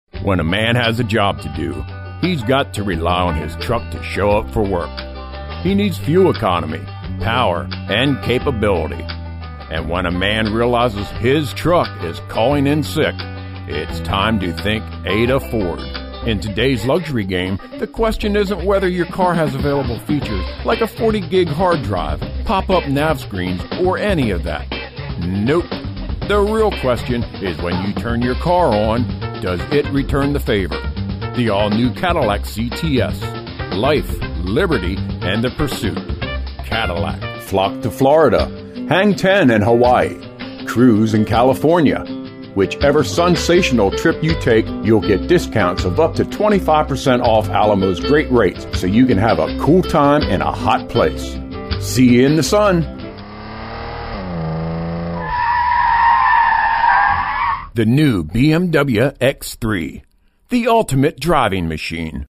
Strong yet pleasing voice, warm, humorous, sincere, professional, believable.
Smooth,warm voice for narration.
Sprechprobe: Werbung (Muttersprache):